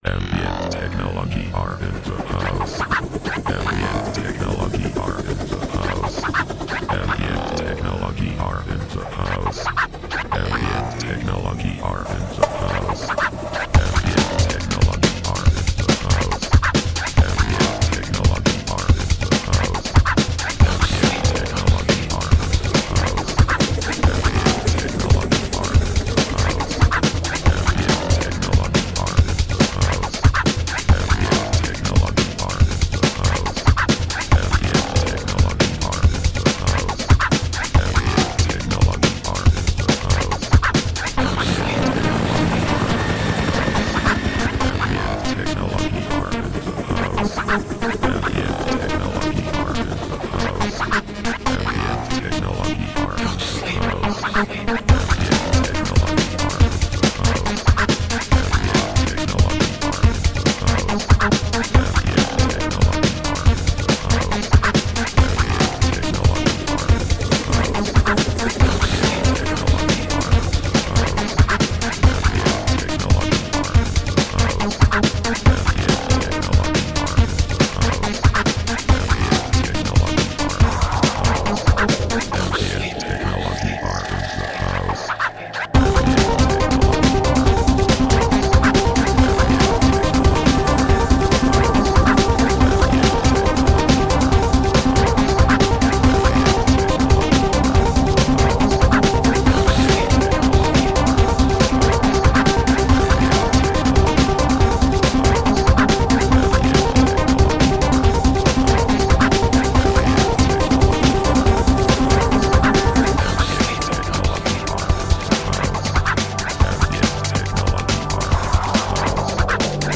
Upbeat underground with shades of new wave.
Tagged as: Electronica, Techno, IDM